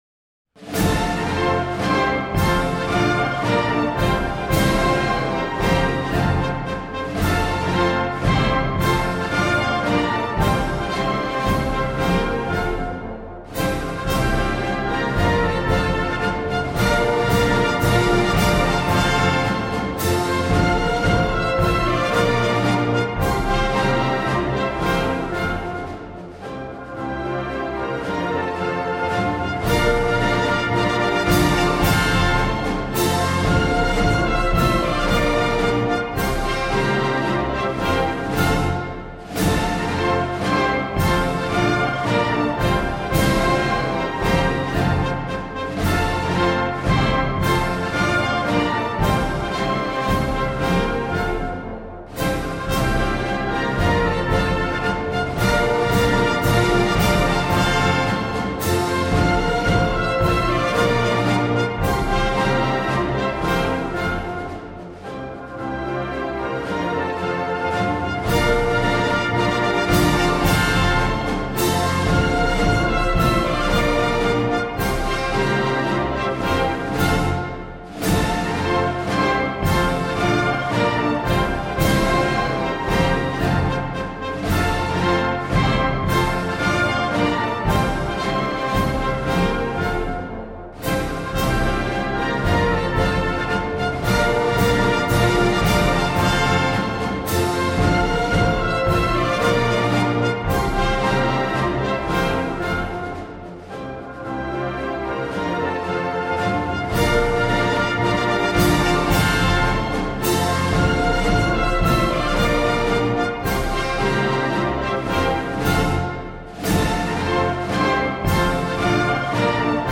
Wersja instrumentalna
Wersja-instrumentalna-caly-hymn-1-wersja.mp3